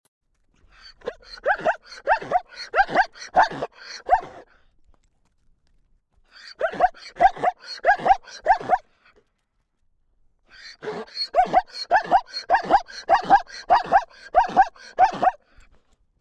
1. True or False: A canine makes these barking sounds.
This barking/braying sound is produced by zebras to express curiosity, communicate with their mates, express anger or assert dominance.
zebra-bray_animal-sounds.mp3